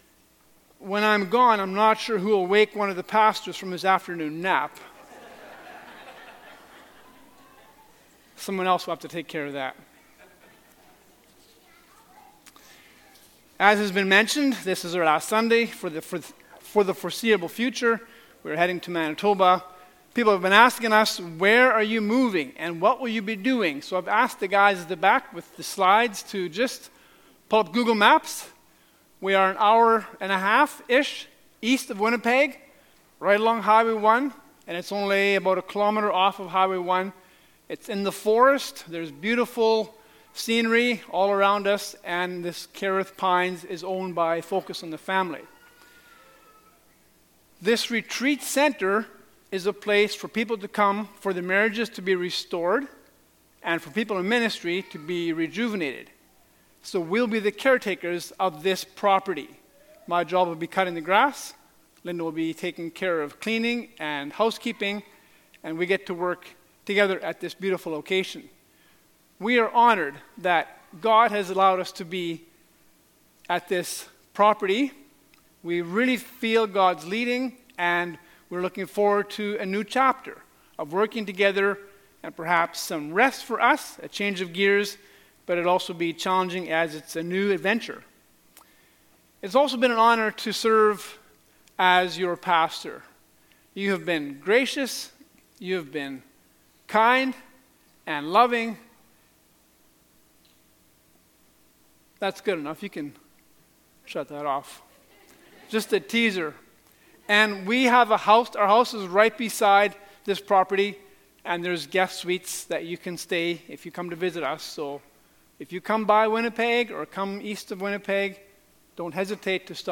Truth Under Fire Passage: 2 Peter 1:1-15 Service Type: Sunday Morning « A Doxology of Grace No Myths Just Majesty